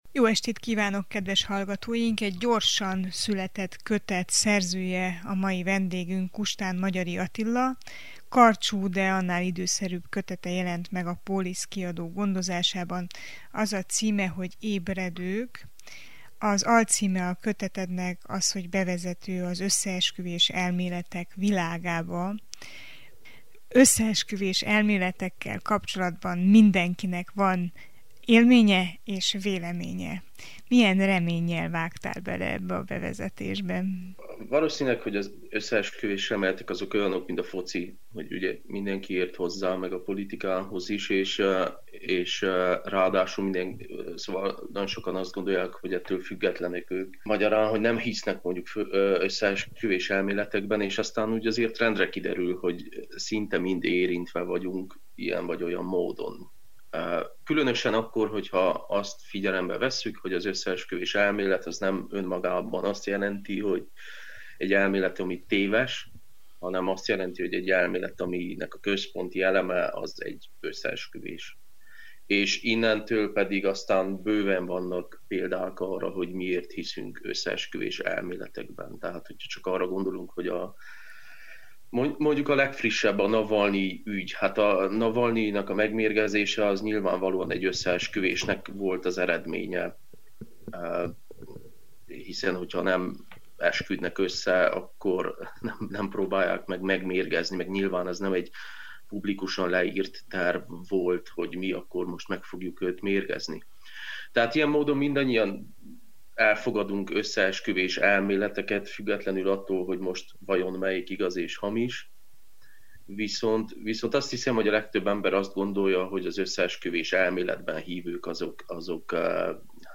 Kell-e megértéssel lenni azok iránt, akik elhiszik a világot irányító sötét erőkről szóló rémtörténeteket? Mi különbözteti meg ezeket a rémtörténeteket a valós társadalmi kritikáktól, leleplezésektől? A szerzővel rögzített beszélgetésünk alább hallgatható meg: